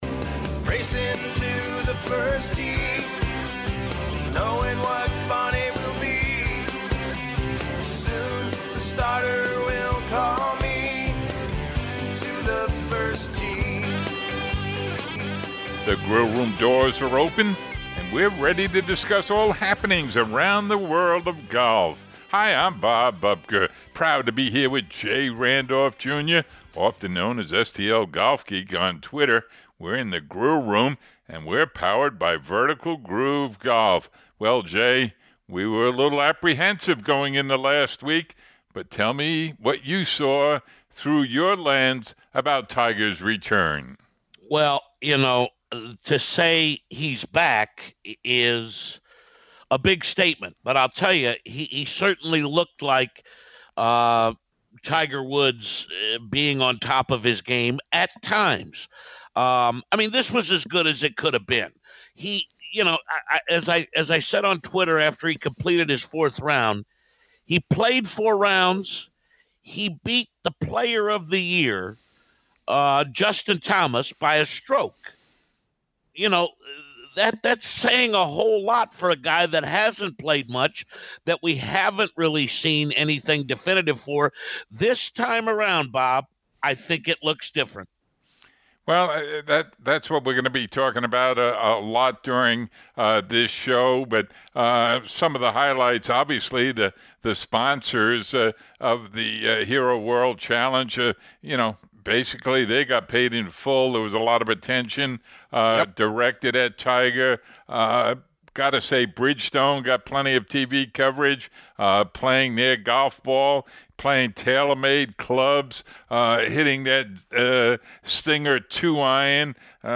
Tiger and Rickie both talk about their performance.